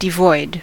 devoid: Wikimedia Commons US English Pronunciations
En-us-devoid.WAV